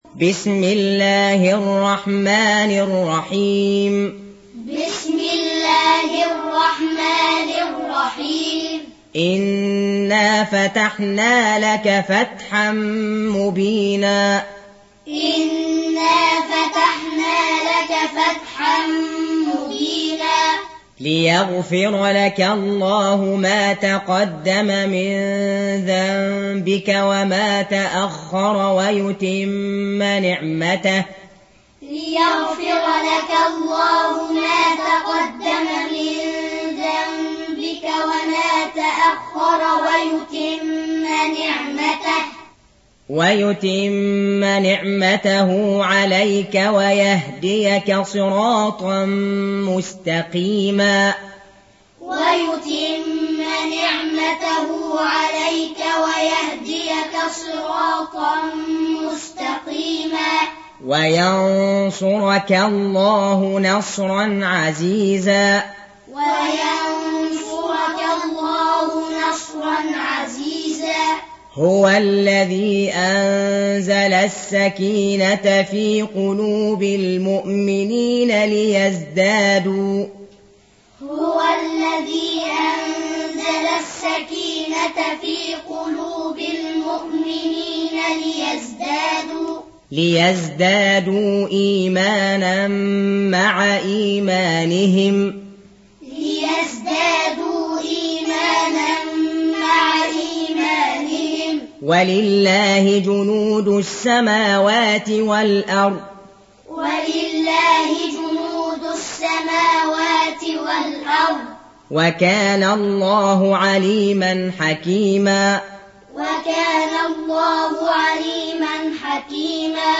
Audio Quran Taaleem Tutorial Recitation Teaching Qur'an One to One
Surah Repeating تكرار السورة Download Surah حمّل السورة Reciting Muallamah Tutorial Audio for 48. Surah Al-Fath سورة الفتح N.B *Surah Includes Al-Basmalah Reciters Sequents تتابع التلاوات Reciters Repeats تكرار التلاوات